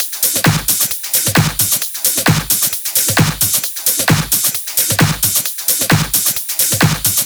VFH3 132BPM Elemental Kit 2.wav